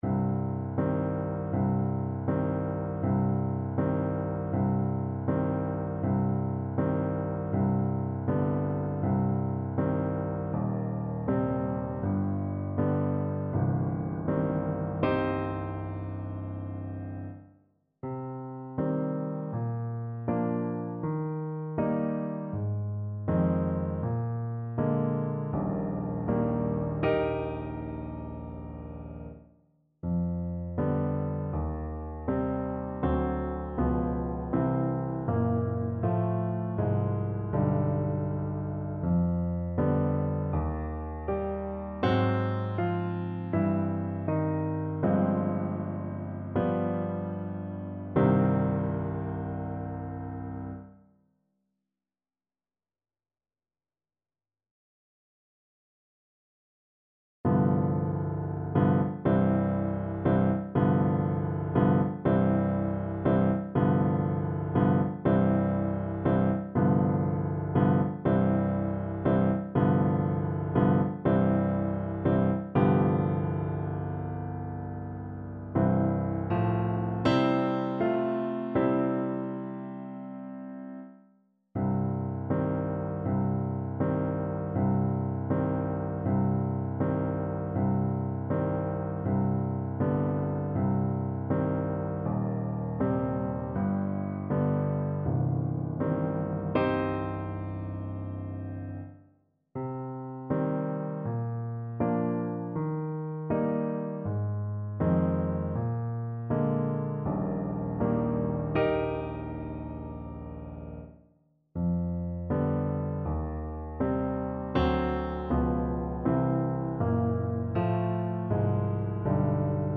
• Unlimited playalong tracks
Un poco andante
4/4 (View more 4/4 Music)
Classical (View more Classical Saxophone Music)